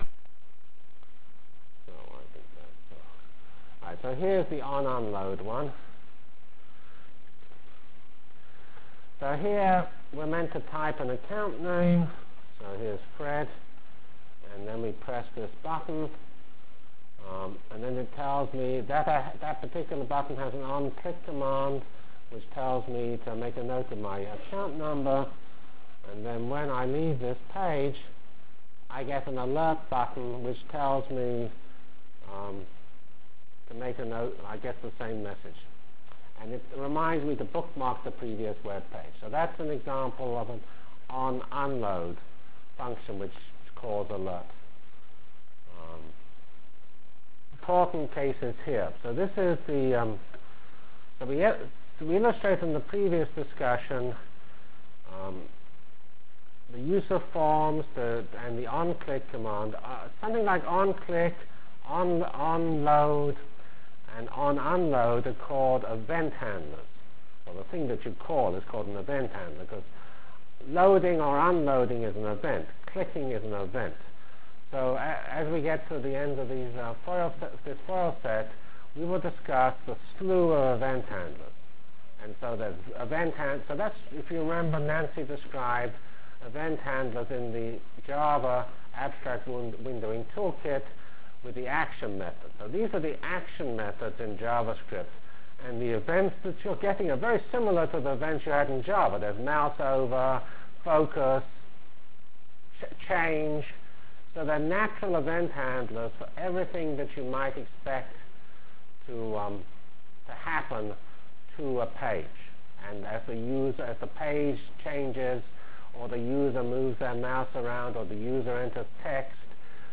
Full HTML for GLOBAL Feb 12 Delivered Lecture for Course CPS616 -- Basic JavaScript Functionalities and Examples